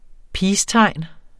Udtale [ ˈpiːsˌtɑjˀn ]